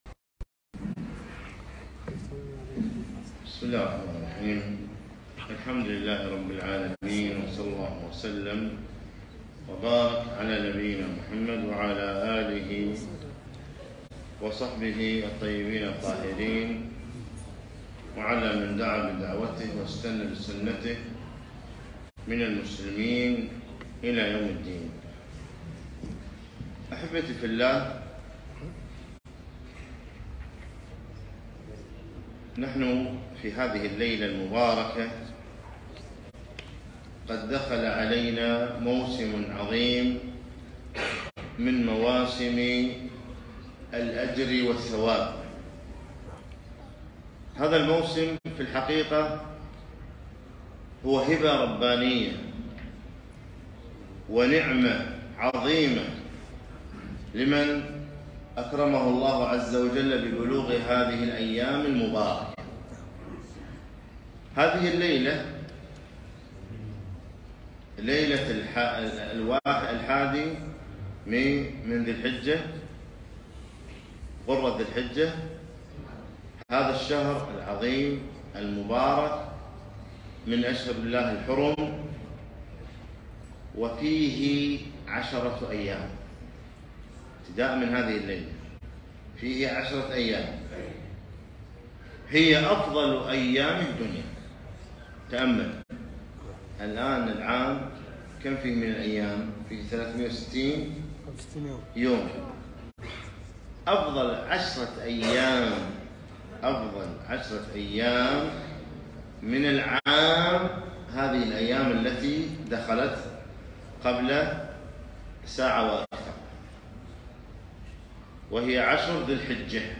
محاضرة - عشر من ذي الحجة فضائل وأحكام